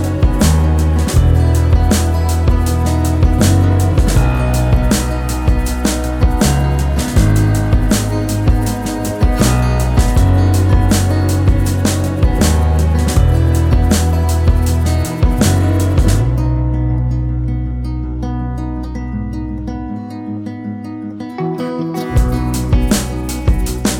Minus Main Guitar Pop (2010s) 3:49 Buy £1.50